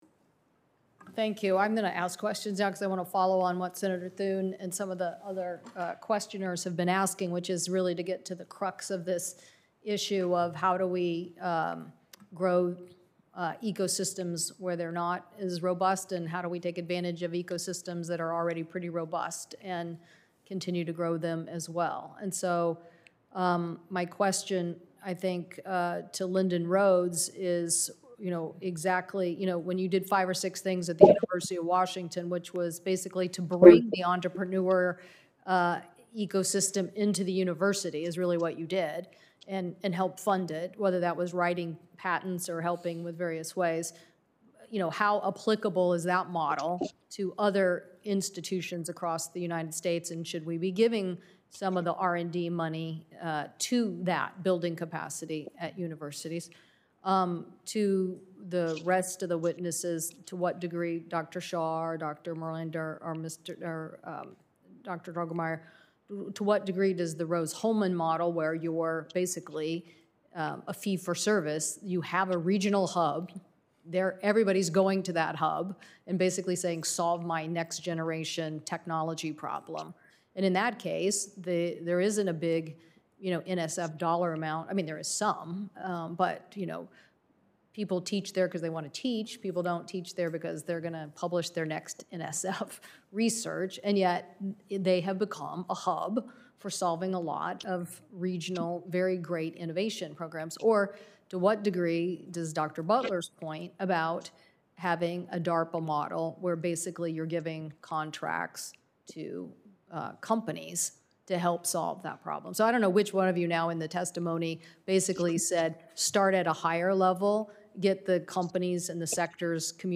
WASHINGTON, D.C.— U.S. Senator Maria Cantwell (D-WA), Chair of the Senate Committee on Commerce, Science, and Transportation, today held a hearing on the “Endless Frontier Act” which seeks to enhance American competitiveness in the area of research and development.
Video of Chair Cantwell’s Q&A with witnesses can be found HERE and audio is